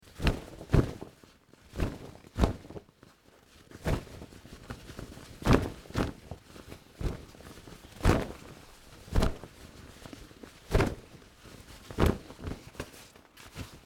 Звуки футболки
Шорох отряхивания футболки от пыли